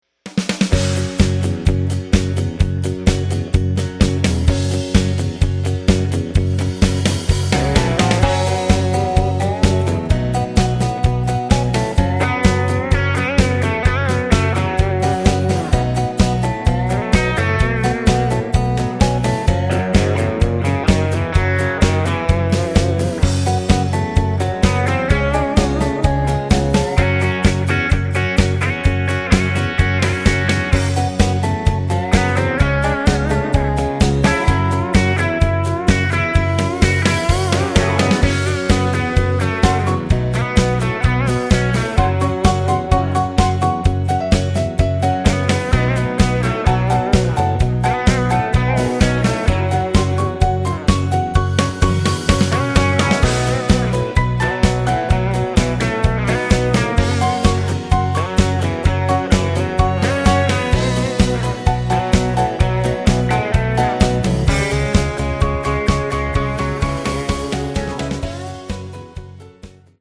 Square Dance Music